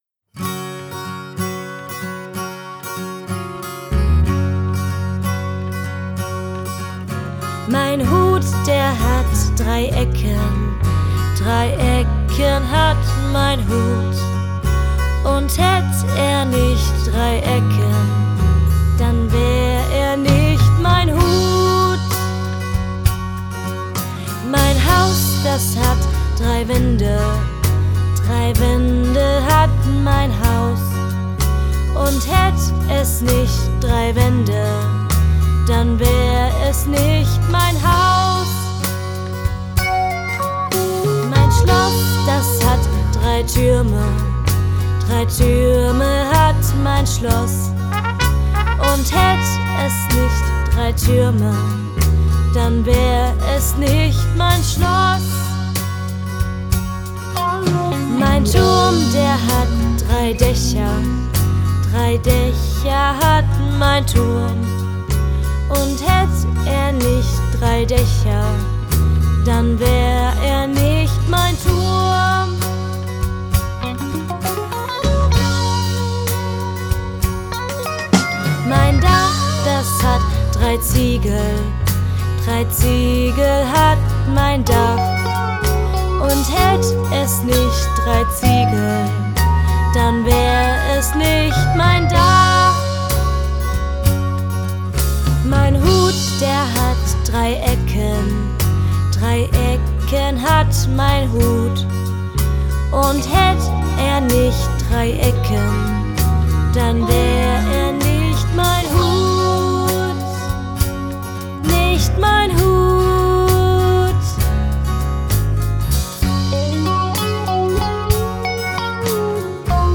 Traditionelle Lieder